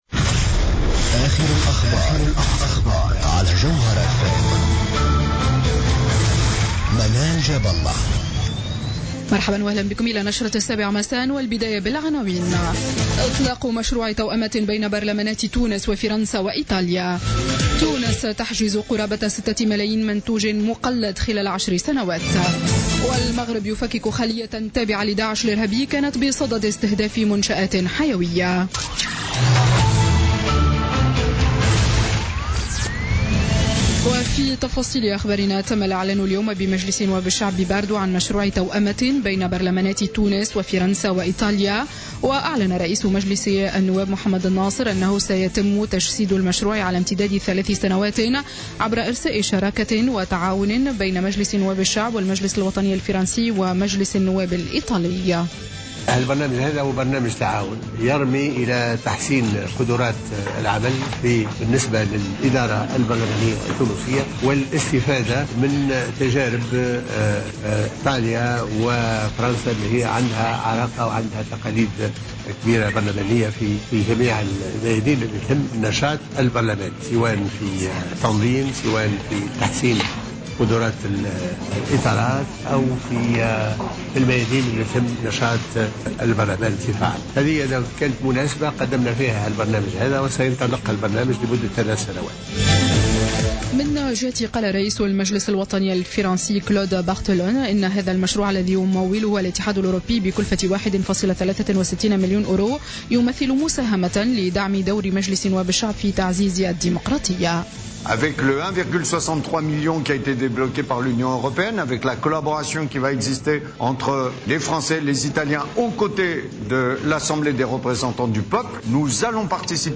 نشرة أخبار السابعة مساء ليوم الجمعة 29 أفريل 2016